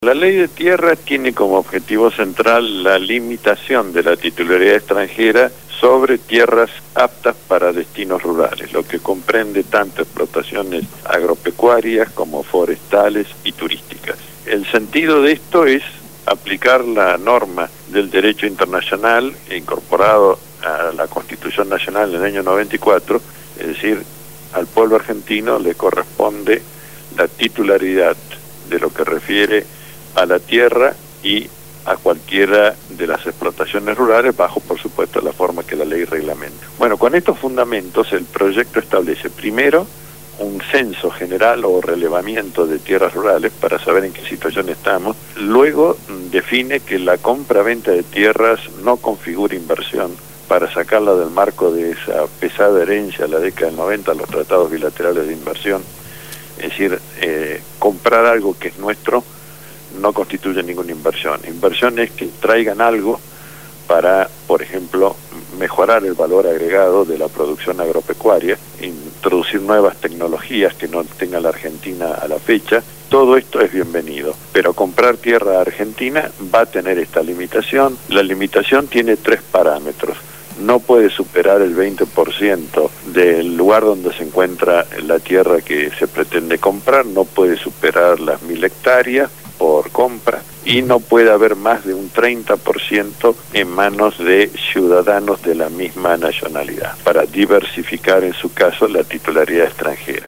ABOGADO CONSTITUCIONALISTA